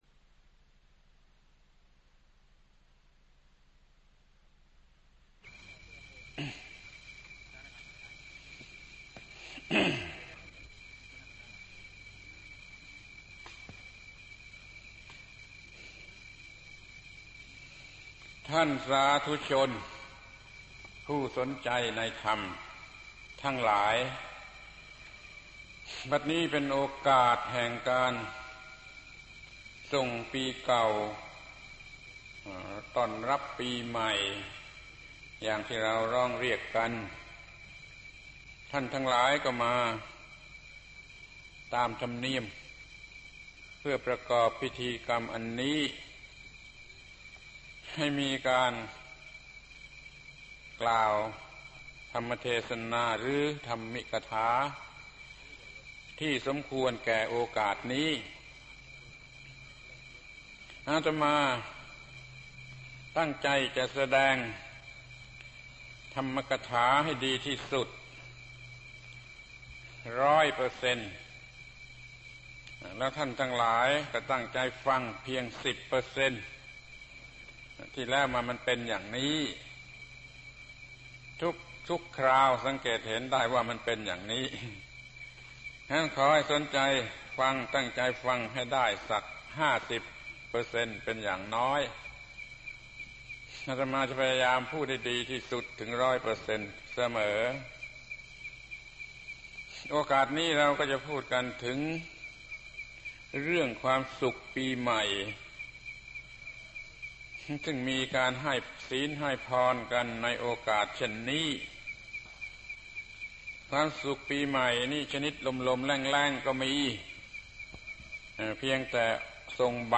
พระธรรมโกศาจารย์ (พุทธทาสภิกขุ) - แสดงธรรมวันสิ้นปี ปี 2523 ศรัทธา